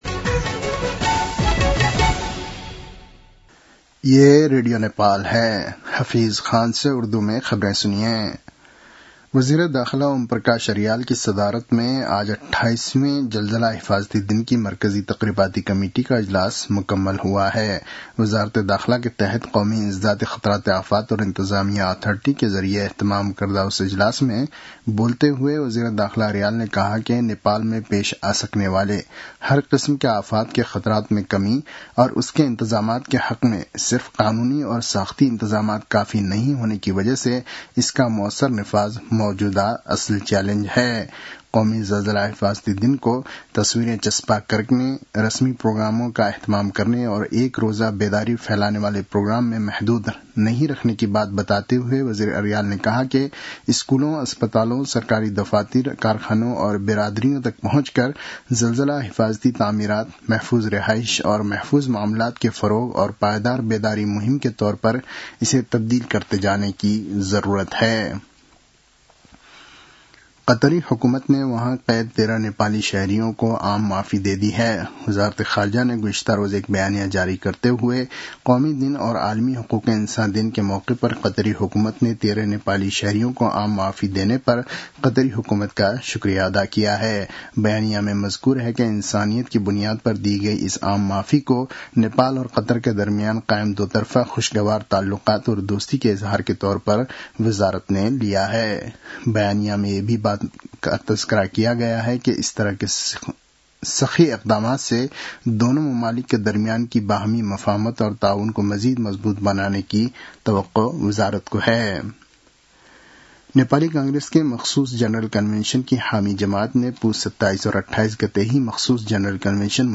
उर्दु भाषामा समाचार : १८ पुष , २०८२
Urdu-NEWS-09-18.mp3